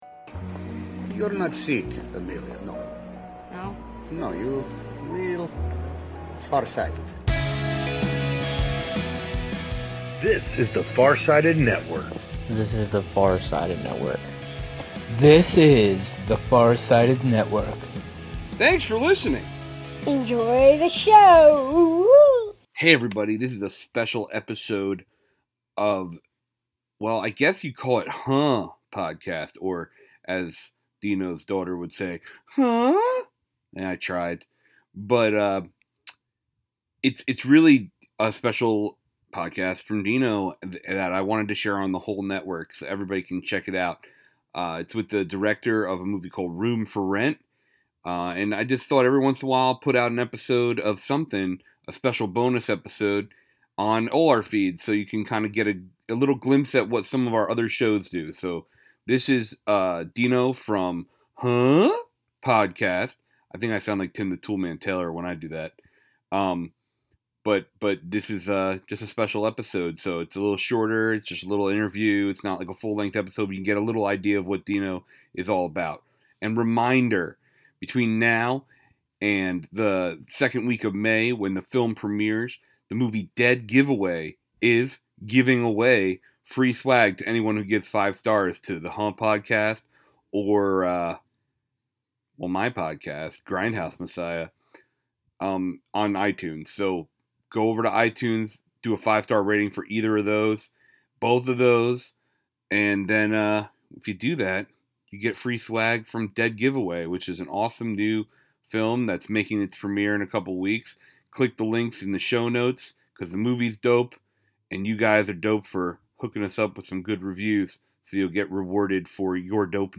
BONUS: Special Interview